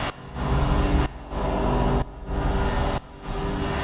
Index of /musicradar/sidechained-samples/125bpm